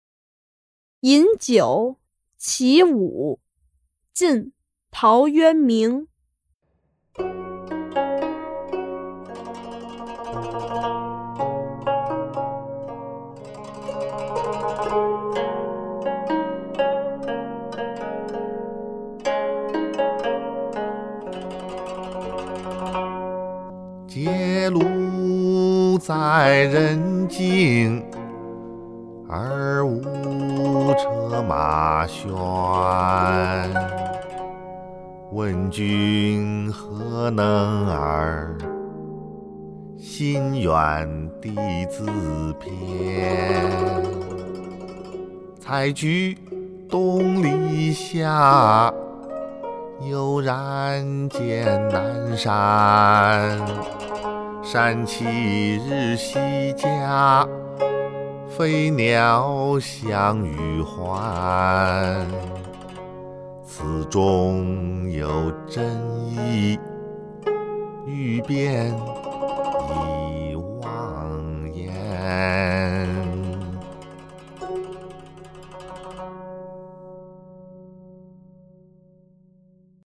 ［晋］陶渊明 《饮酒》 （其五）（吟咏）